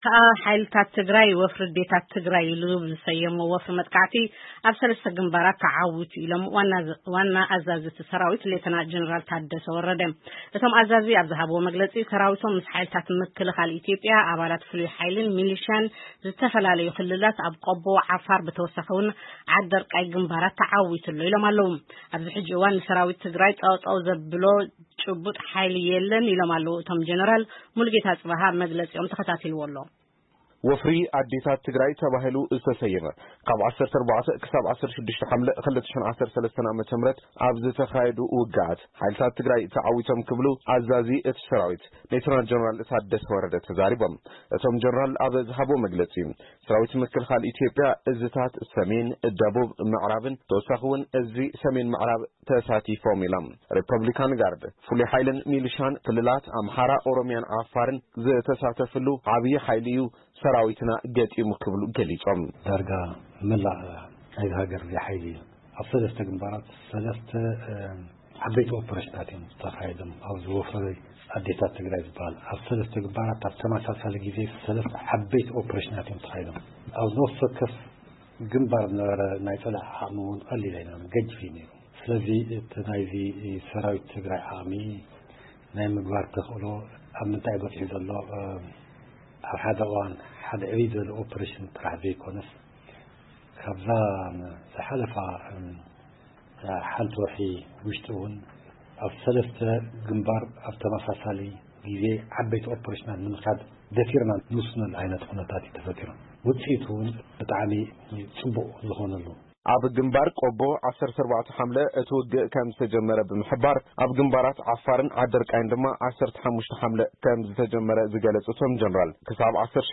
መግለጺ ኣዛዚ ሓይልታት ትግራይ ሌተናል ጀነራል ታደሰ